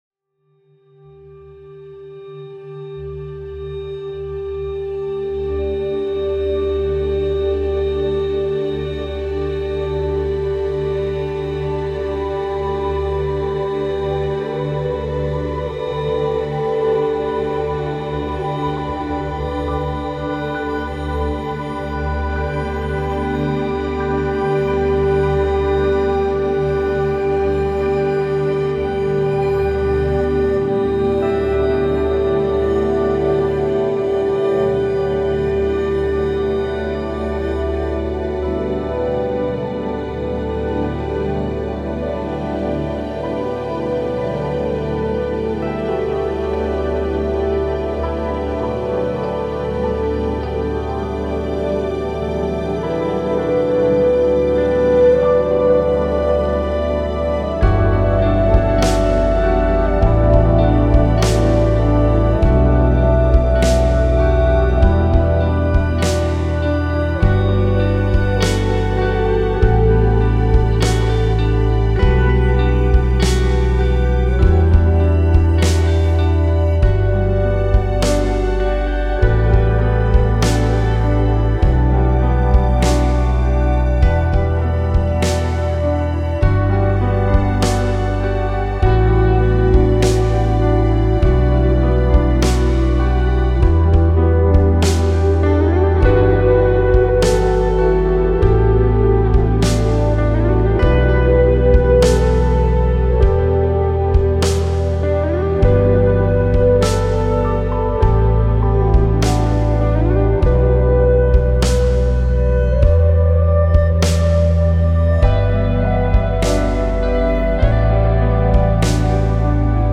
This track will be part of a new collection of ambient post-rock songs I’ve written and recorded, hopefully to be released this fall.